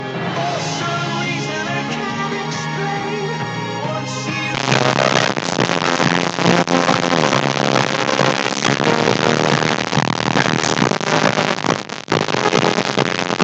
As you will notice, a lot of signal bleeds through.